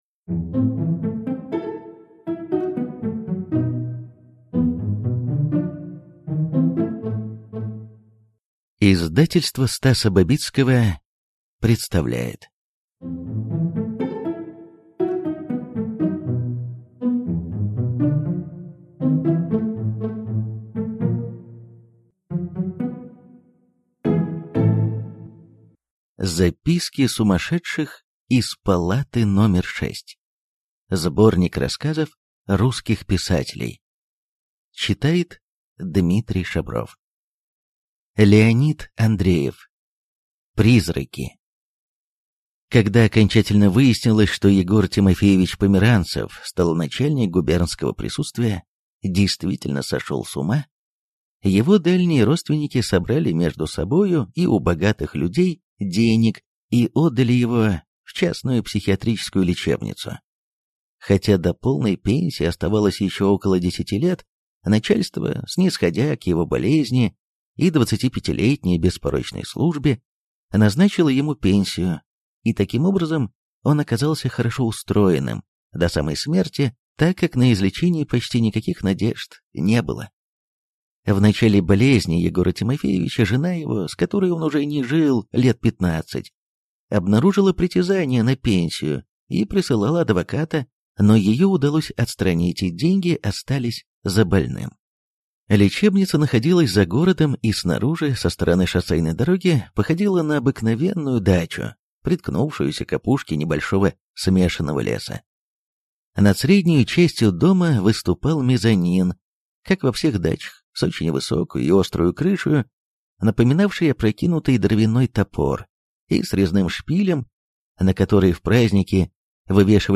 Аудиокнига Записки сумасшедших из палаты номер шесть | Библиотека аудиокниг